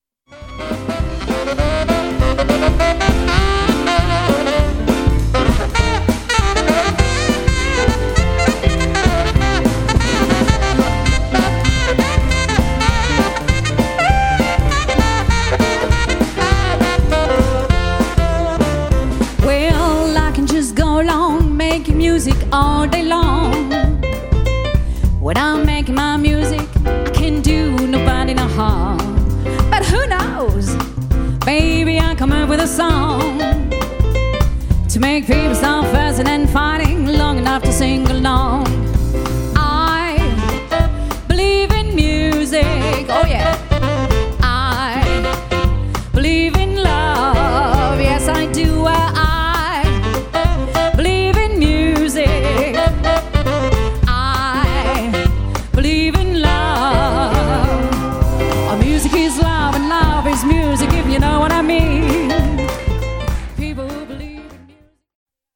chant
saxophone ténor
piano
contrebasse
batterie